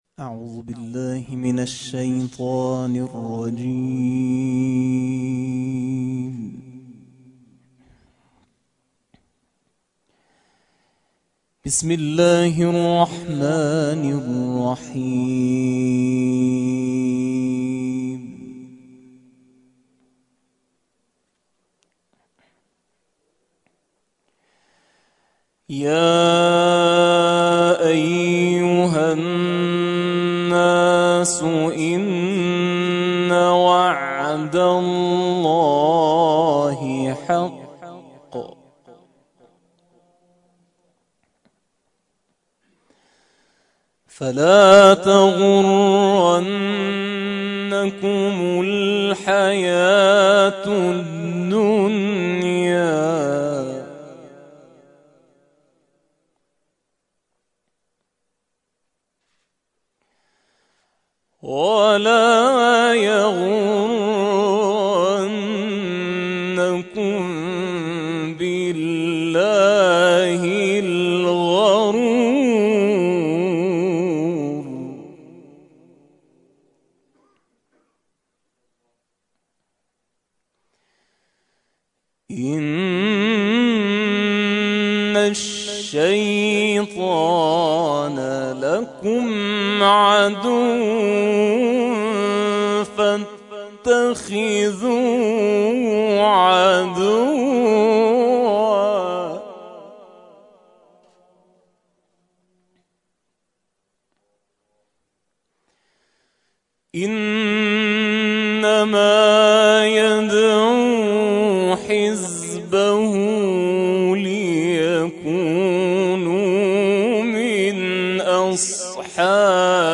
کرسی تلاوت تسنیم